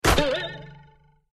wood_hit_03.ogg